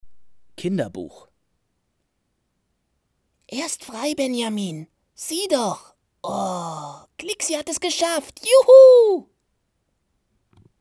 klare, sanfte und ausdrucksstarke Stimme mit Wiedererkennungswert / auch Trickstimme
Sprechprobe: Sonstiges (Muttersprache):